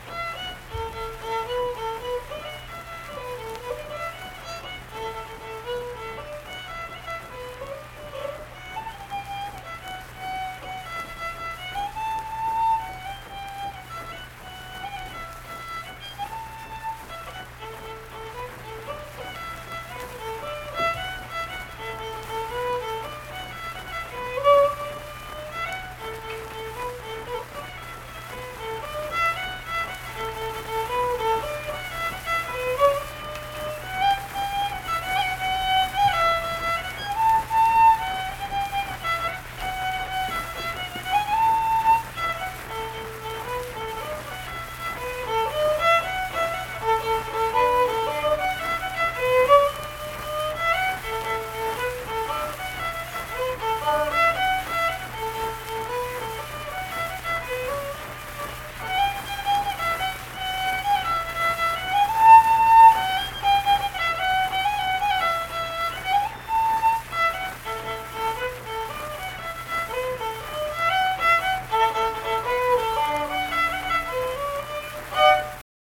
Unaccompanied fiddle music performance
Instrumental Music
Fiddle
Harrison County (W. Va.)